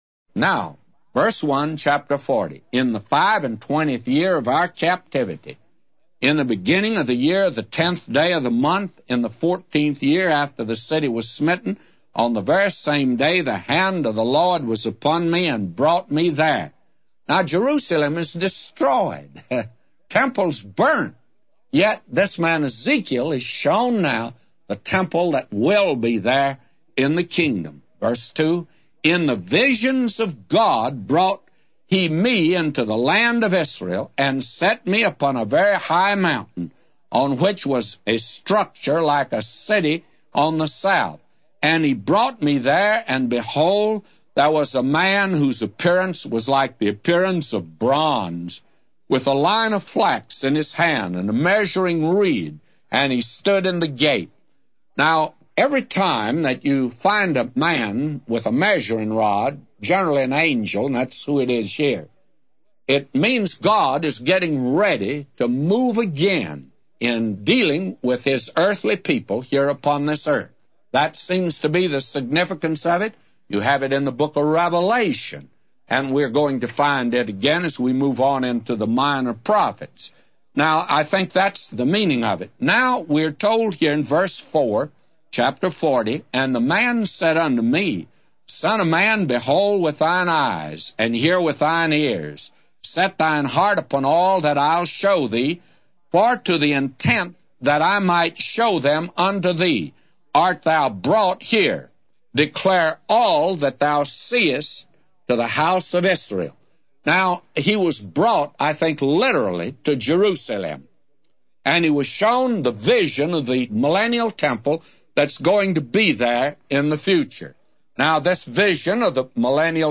A Commentary By J Vernon MCgee For Ezekiel 40:1-999